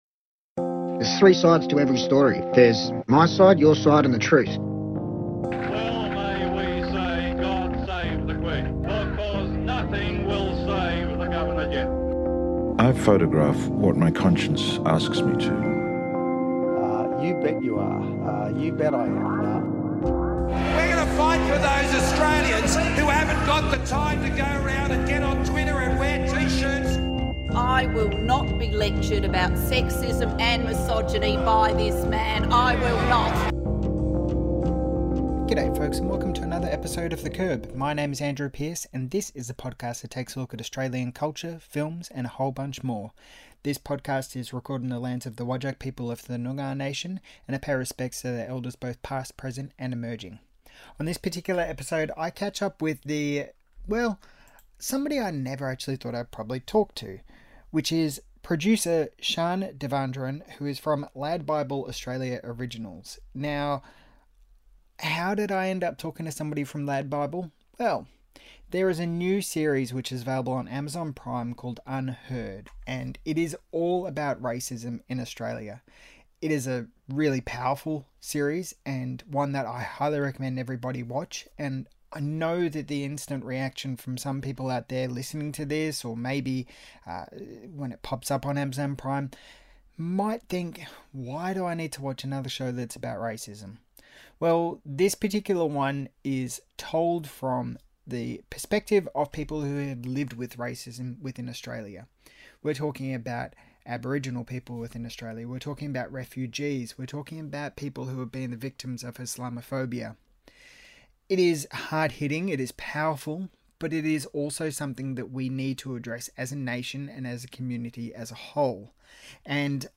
The Sixth Reel Co-Director Charles Busch Celebrates Classic Hollywood, Queer Icons, and More in This Interview